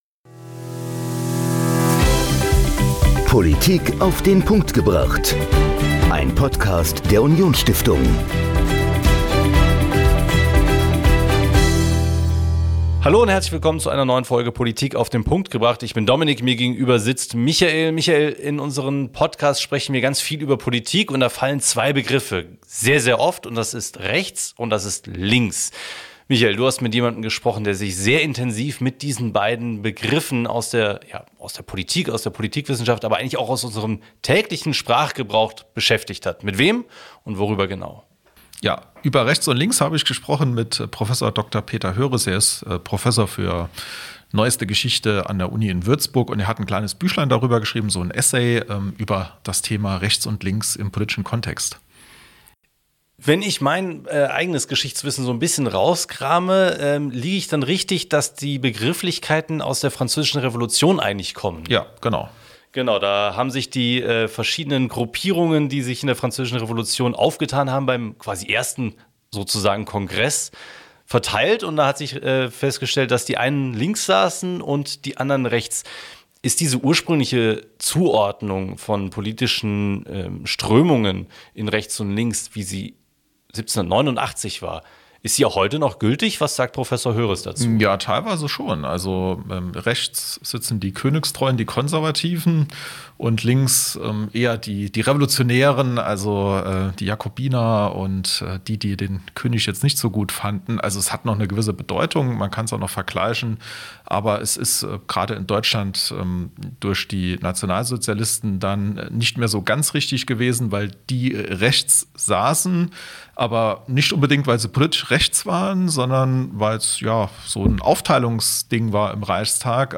Im Gespräch über das Buch „Rechts und links“